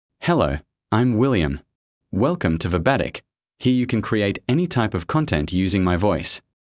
William — Male English (Australia) AI Voice | TTS, Voice Cloning & Video | Verbatik AI
MaleEnglish (Australia)
William is a male AI voice for English (Australia).
Voice sample
William delivers clear pronunciation with authentic Australia English intonation, making your content sound professionally produced.